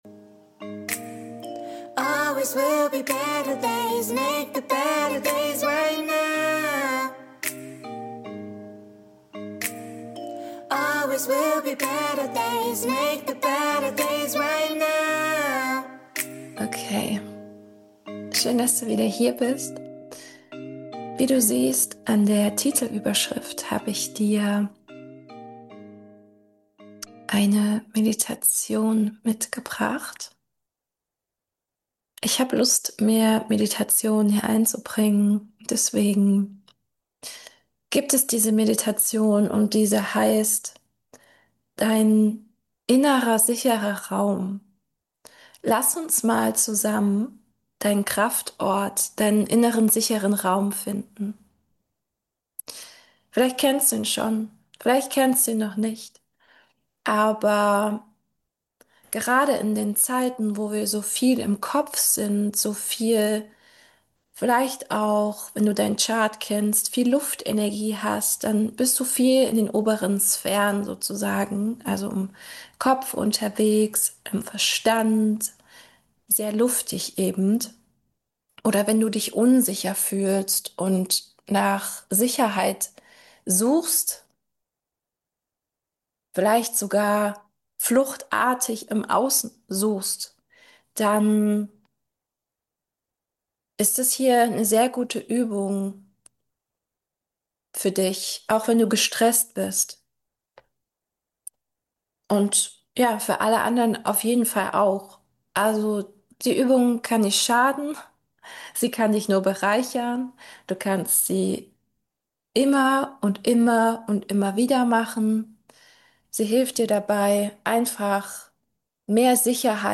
#63 Meditation ~ Innerer Safe Space ~ Soul.Mind.Waves Podcast
Lass dich von meiner Stimme führen und schau was sich dir zeigt. In dieser kraftvollen Meditation nutzen wir auch einen Anker, den du dir als Stütze und Reminder besorgen kannst - vielleicht als Schlüsselanhänger oder kleine Figur.